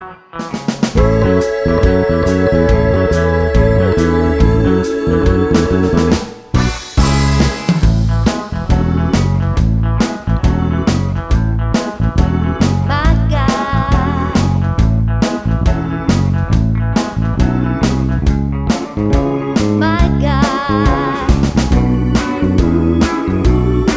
For Solo Singer Soundtracks 3:15 Buy £1.50